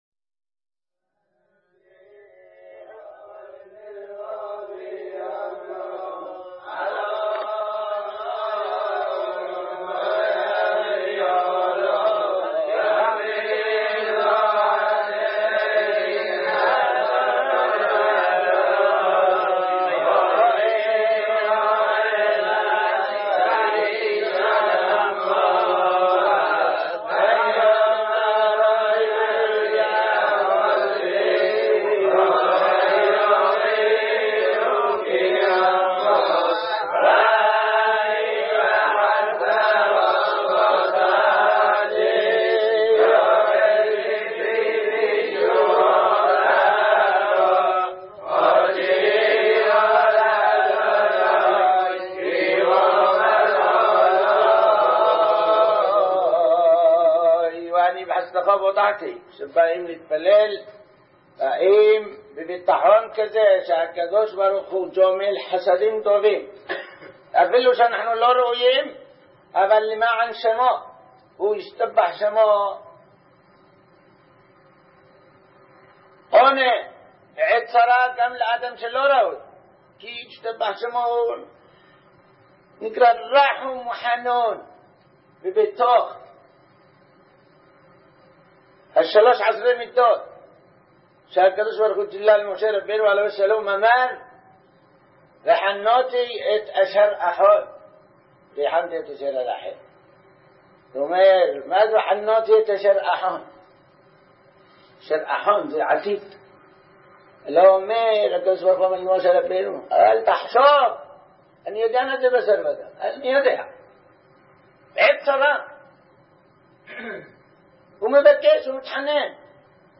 חמש עשרה קטעי אשמורות (ברצף)
בשילוב דברי מוסר בין קטע לקטע